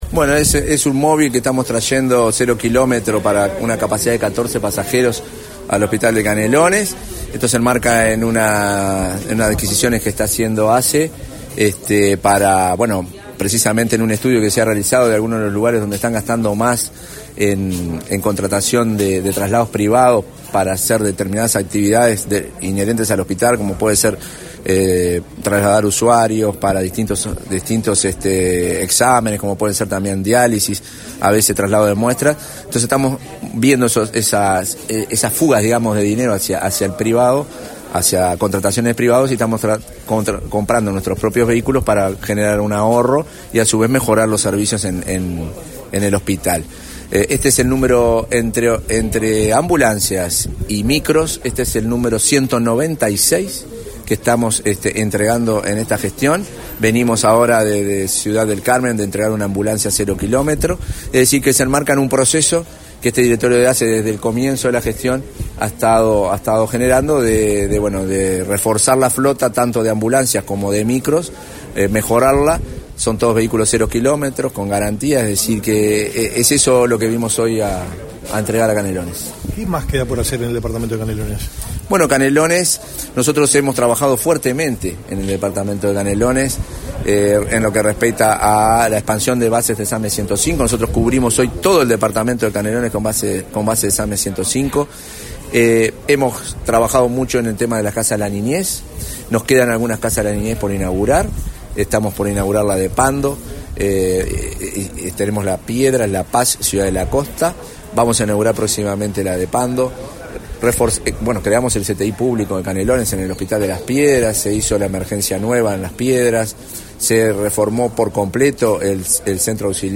Declaraciones a la prensa del presidente de ASSE, Marcelo Sosa
Declaraciones a la prensa del presidente de ASSE, Marcelo Sosa 05/06/2024 Compartir Facebook X Copiar enlace WhatsApp LinkedIn Tras participar en el acto de entrega del vehículo de traslado para uso del hospital de Canelones, este 5 de junio, el presidente de la Administración de los Servicios de Salud del Estado (ASSE), Marcelo Sosa, realizó declaraciones a la prensa.